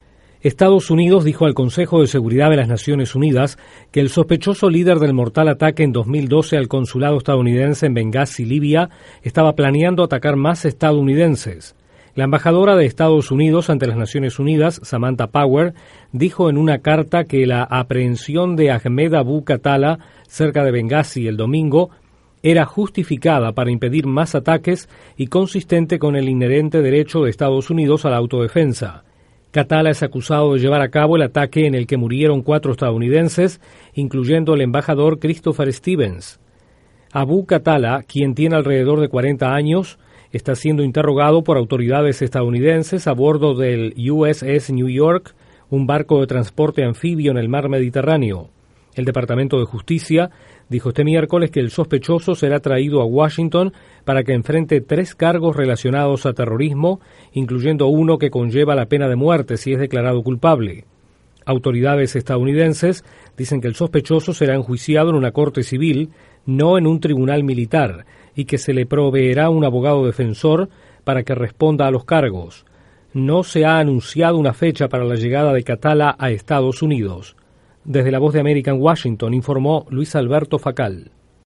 Estados Unidos dice que el sospechoso del ataque al consulado en Benghazi, Libia, hace dos años, planeaba más ataques. Detalles desde la Voz de América en Washington